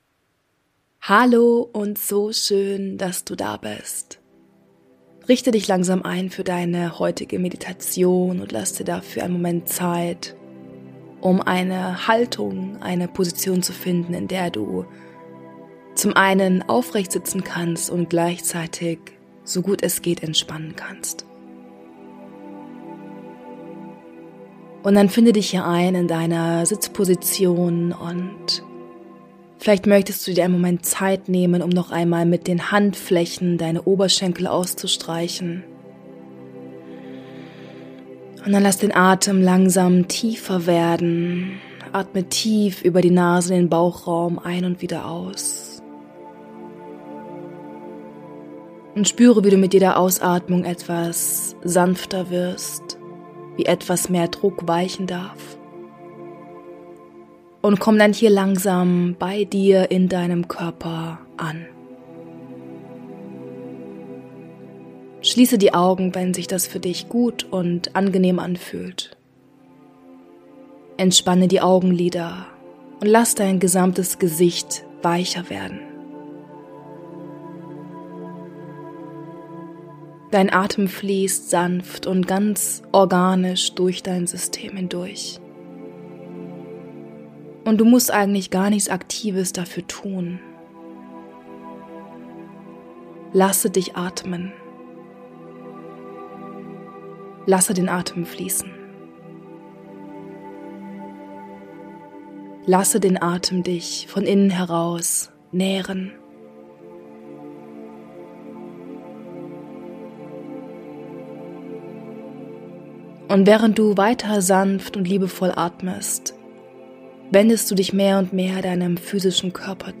Eine Meditation, die dich darin unterstützt, mit einem Gefühl von Klarheit, Verbundenheit und Erdung durch den Tag zu gehen. Nutze die Meditation so gerne am Morgen oder alternativ in einem anderen, ruhigen Moment während des Tages.